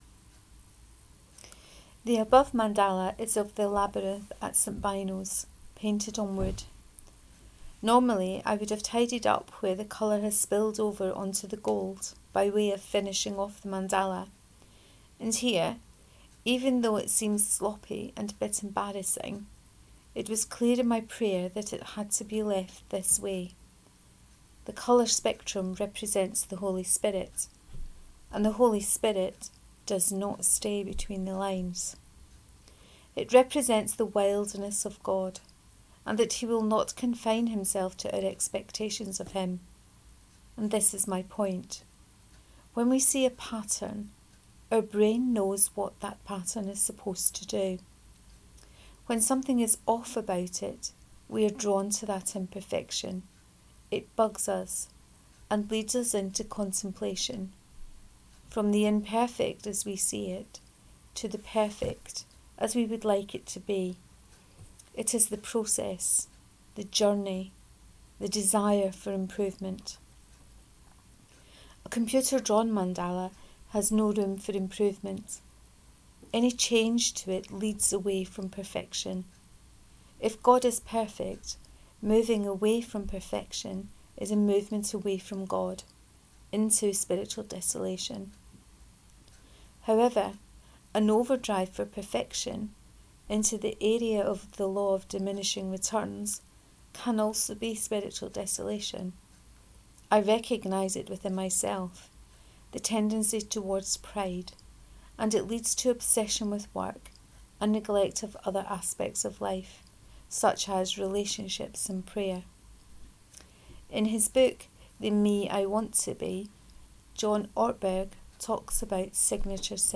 The Perfect Imperfect 5: Reading of this post.